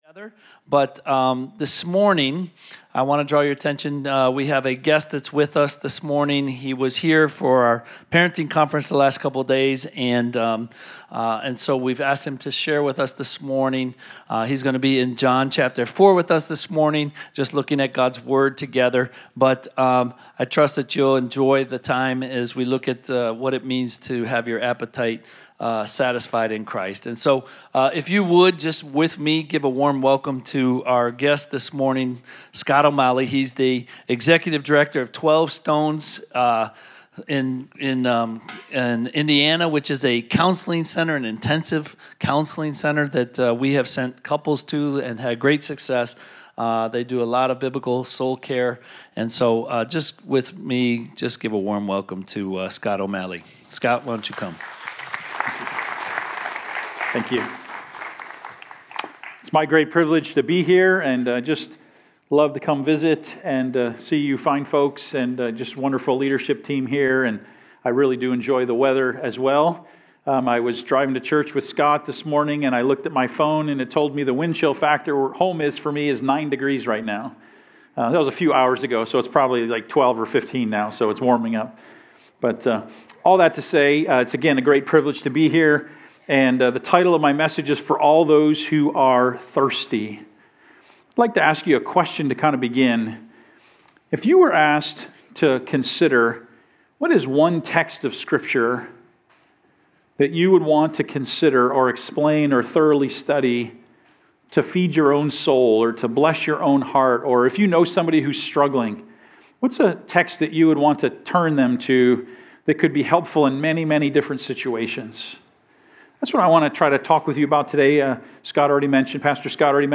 Home Sermons Guest Speaker